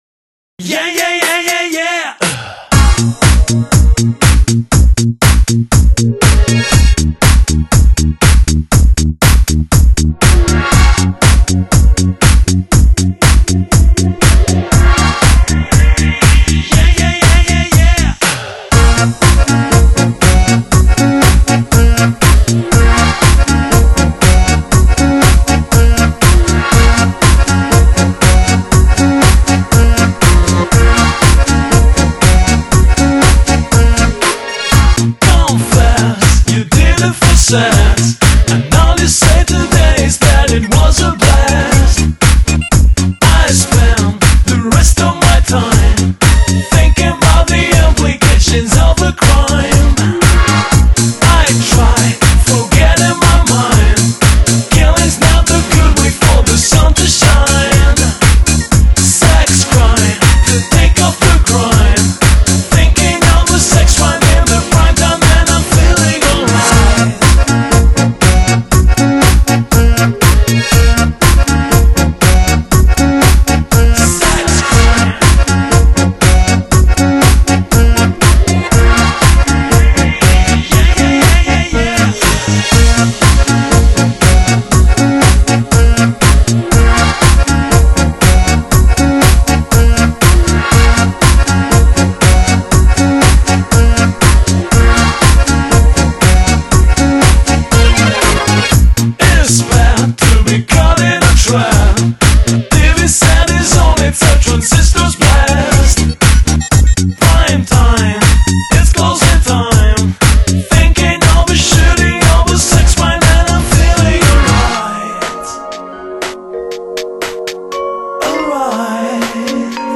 音乐风格：Dance / Electronic